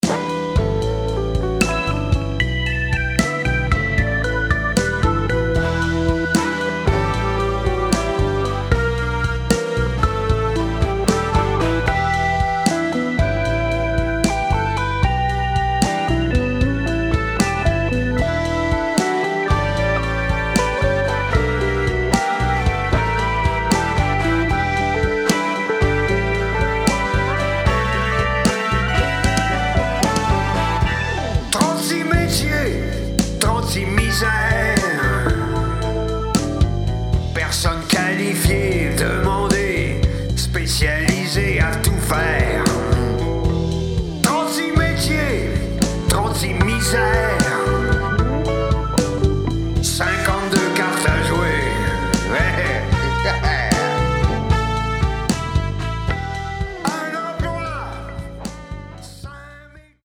chante et  parle
guitariste
album électrique